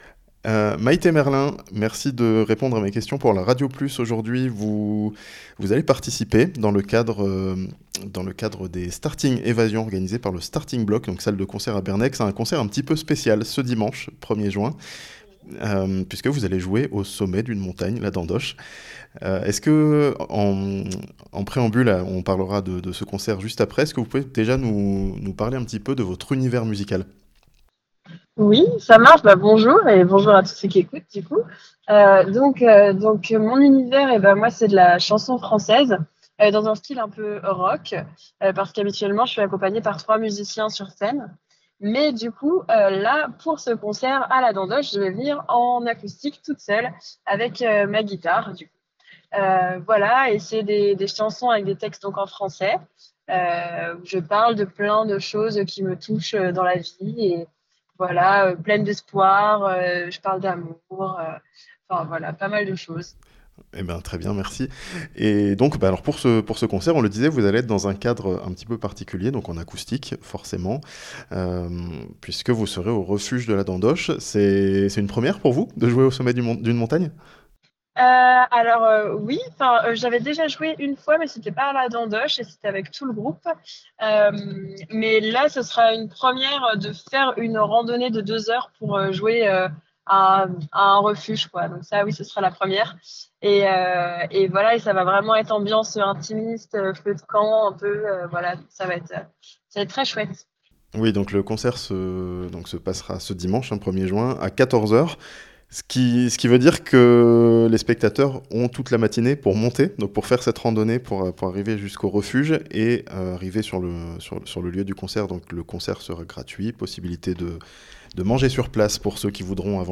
(interview)